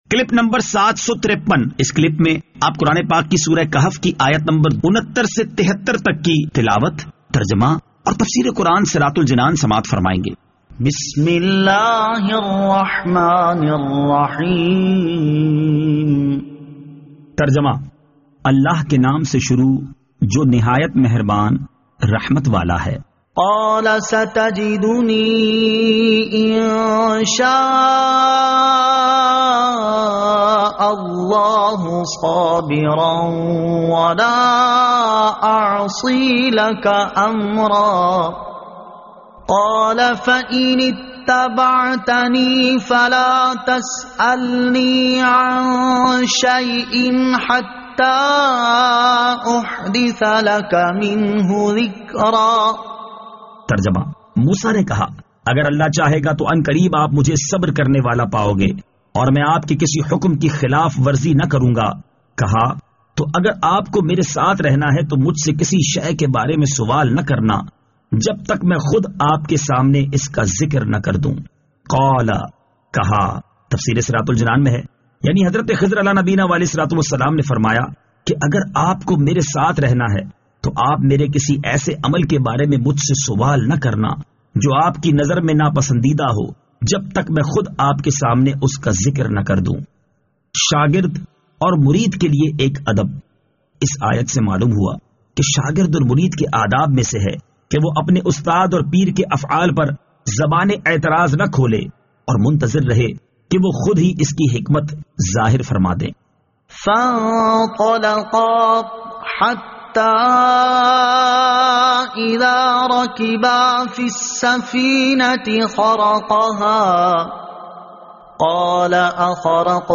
Surah Al-Kahf Ayat 69 To 73 Tilawat , Tarjama , Tafseer